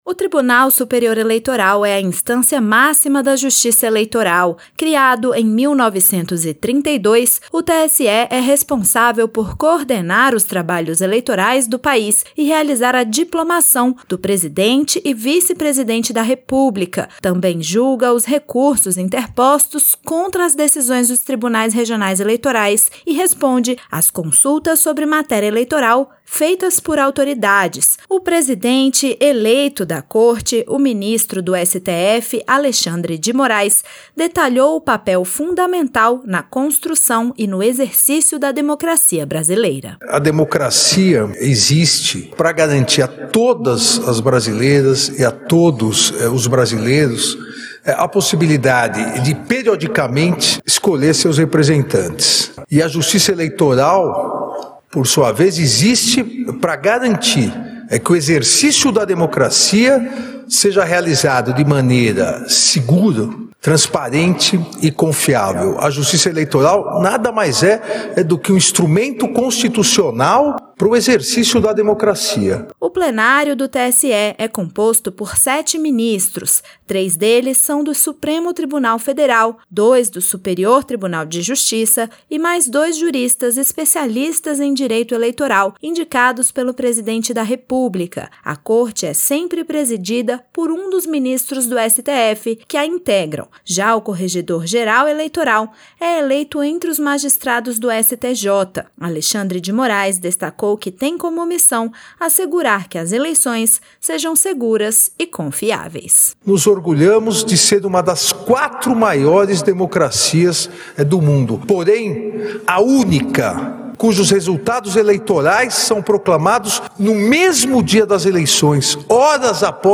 O presidente eleito da Corte, o ministro do STF Alexandre de Moraes, detalhou o papel fundamental na construção e no exercício da democracia brasileira.